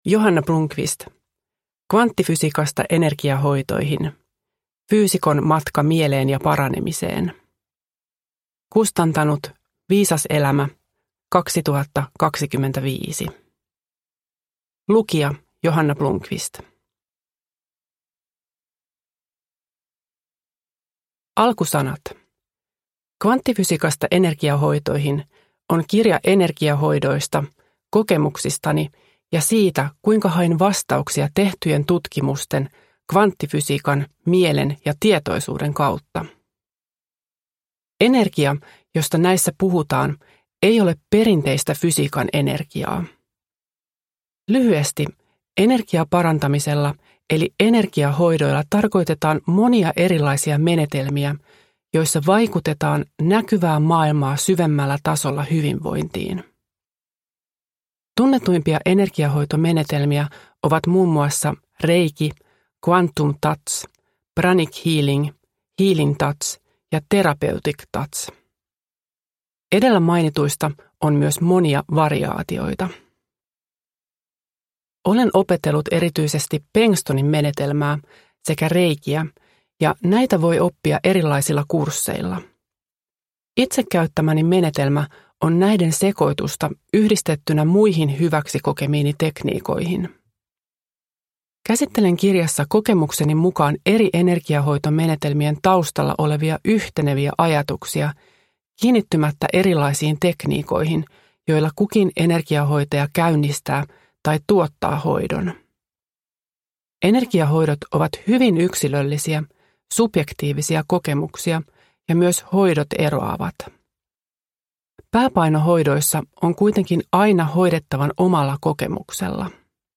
Kvanttifysiikasta energiahoitoihin – Ljudbok